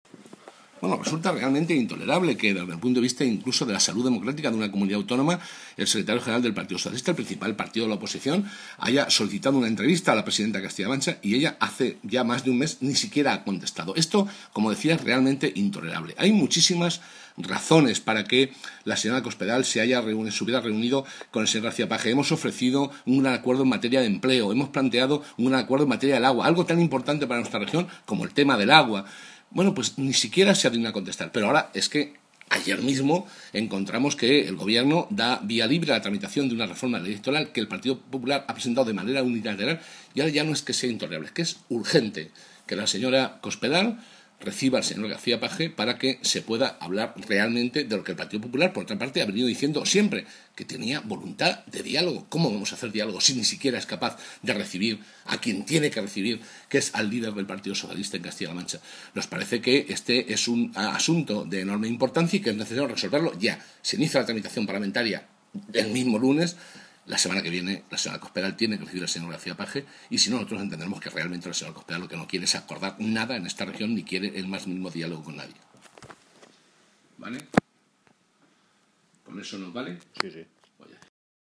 José Molina, diputado regional del PSOE de Castilla-La Mancha
Cortes de audio de la rueda de prensa